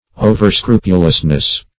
Search Result for " overscrupulousness" : The Collaborative International Dictionary of English v.0.48: Overscrupulousness \O`ver*scru"pu*lous*ness\, n. The quality or state of being overscrupulous; excess of scrupulousness.